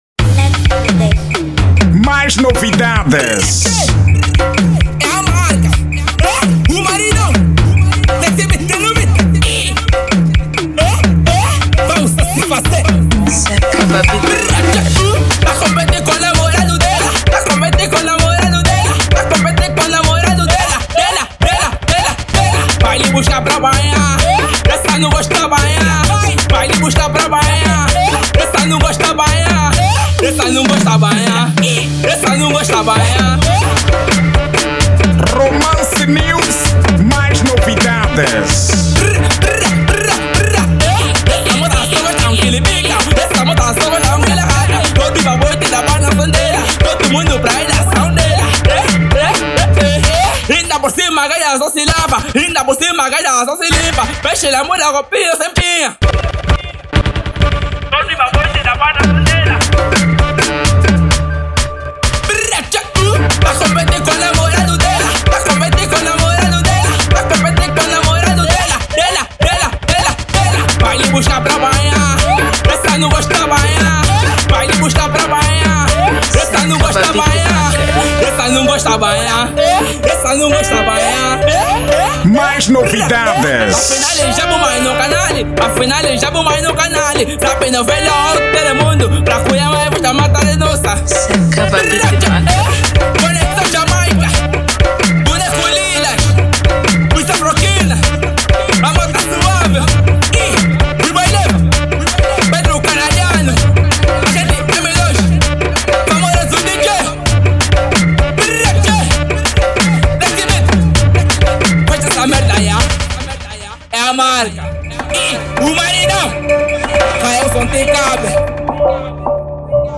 Estilo: Afro-House